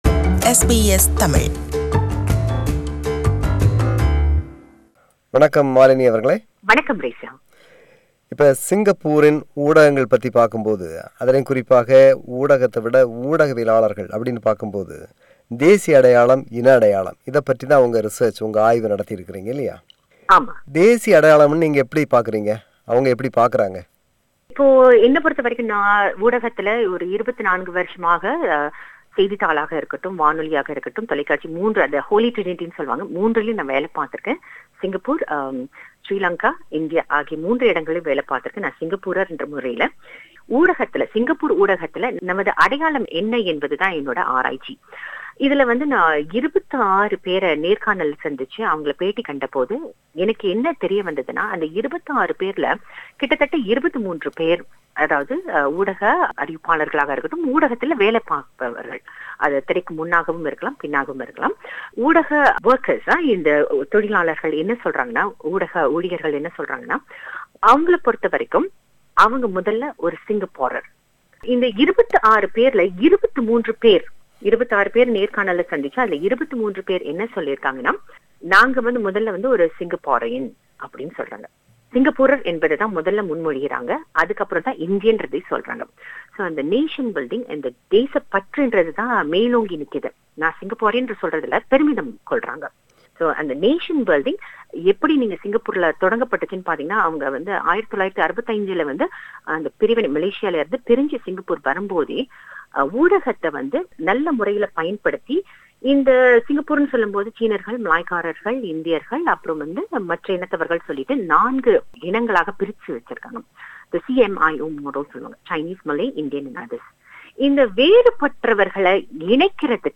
Interview: Part 1.